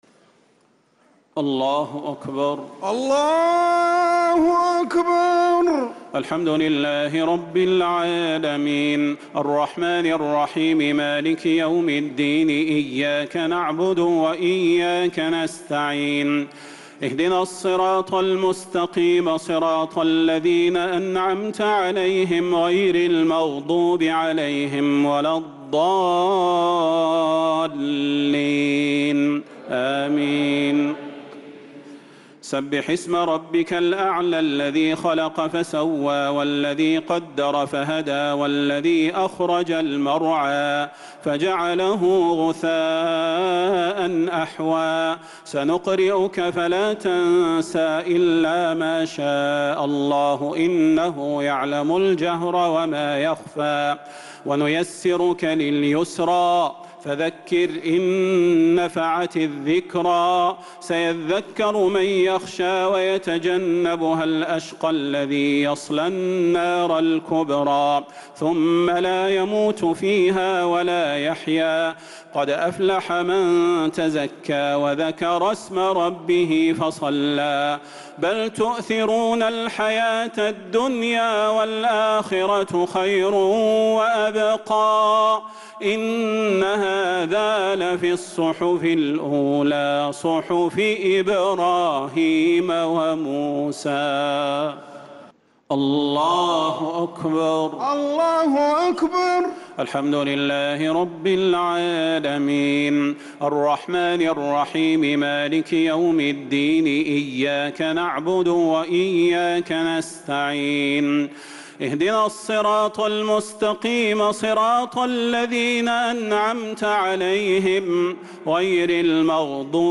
صلاة الشفع و الوتر ليلة 5 رمضان 1446هـ | Witr 5th night Ramadan 1446H > تراويح الحرم النبوي عام 1446 🕌 > التراويح - تلاوات الحرمين